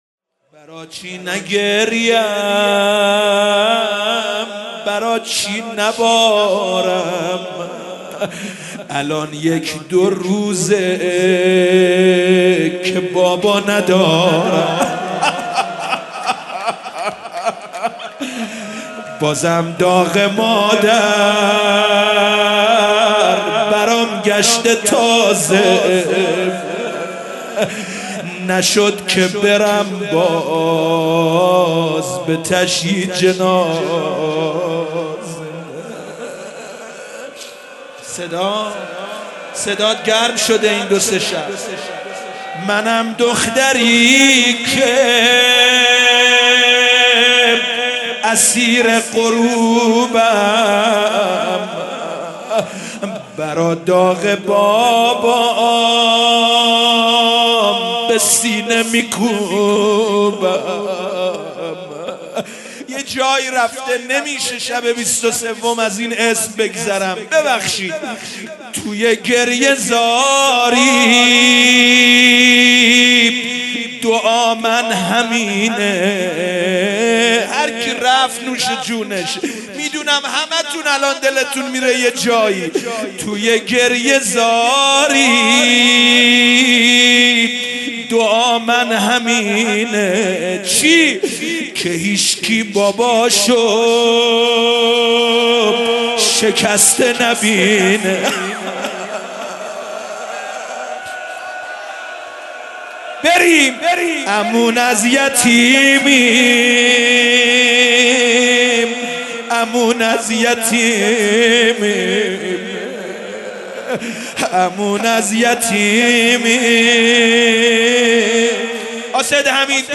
روضه امیر المومنین